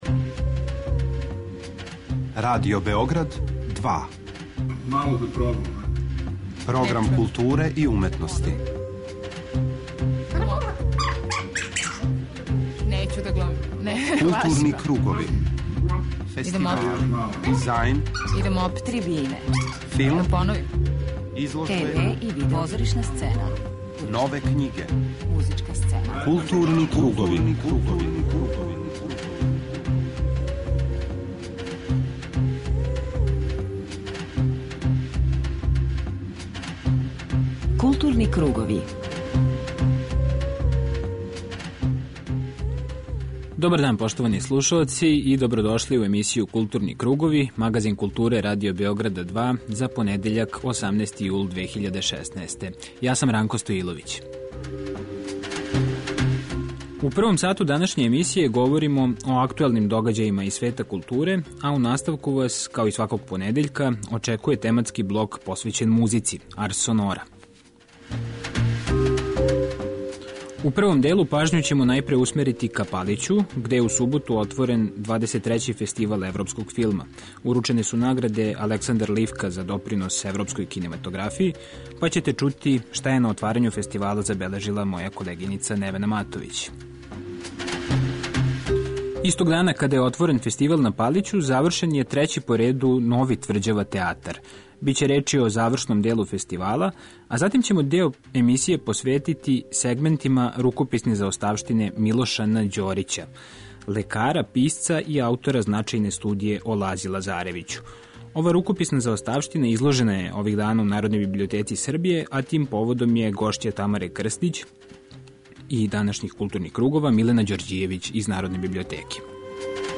преузми : 40.99 MB Културни кругови Autor: Група аутора Централна културно-уметничка емисија Радио Београда 2.